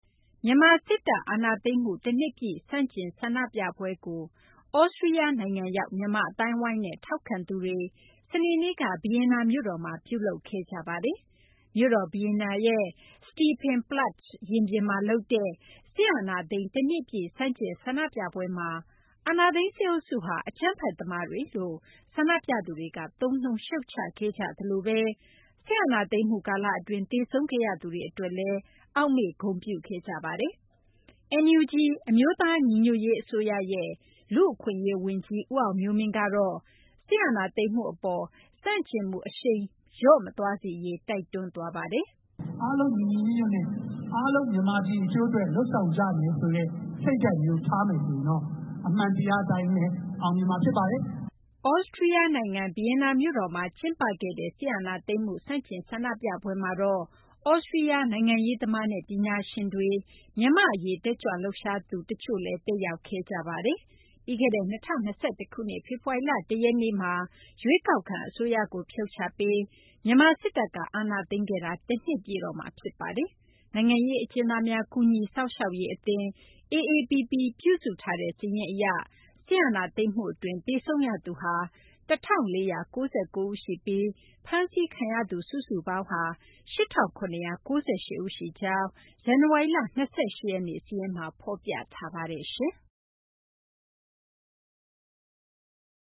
မြန်မာစစ်တပ် အာဏာသိမ်းမှု တနှစ်ပြည့် ဆန့်ကျင် ဆန္ဒပြပွဲကို သြစတြီးယားနိုင်ငံရောက် မြန်မာအသိုင်းအဝိုင်းနဲ့ ထောက်ခံသူတွေ စနေနေ့က ဗီယင်နာမြို့တော်မှာ ပြုလုပ်ခဲ့ကြပါတယ်။